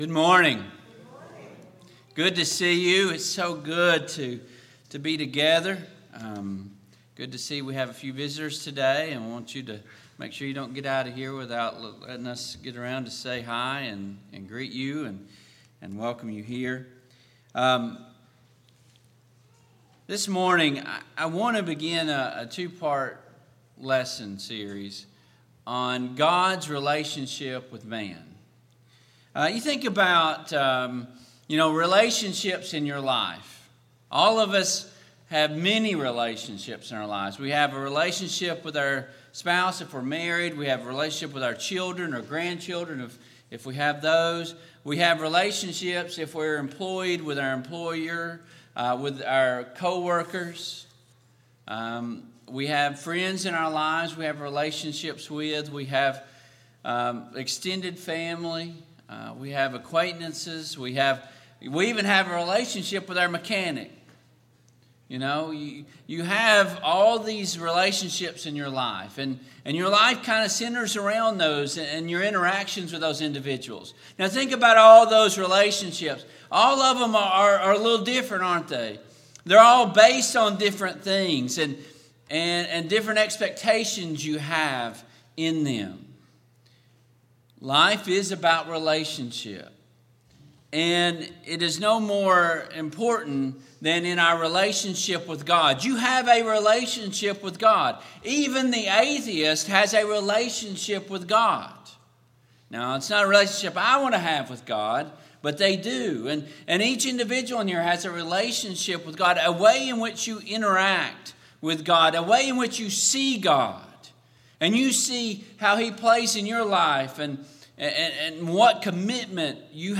Service Type: AM Worship